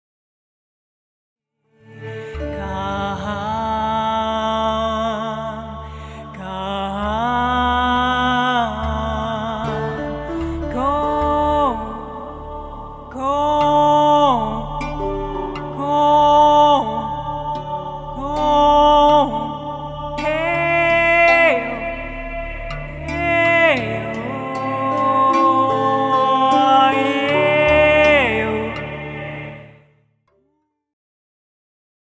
Meditative
Momentum-Aufnahmen